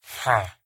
Sound / Minecraft / mob / villager / no3.ogg
should be correct audio levels.